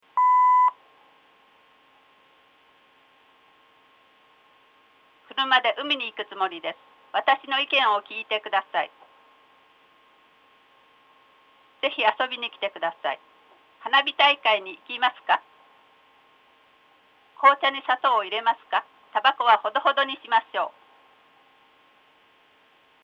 PattXX_LサイレントR女性_ECON_SCON PCステレオ信号出力のLch無音、Rch女声の組み合わせです。
ECとSCが効いています。 エコーはないので、SCの効果がわかります。
Patt01_LサイレントR女性_ECON_SCON.mp3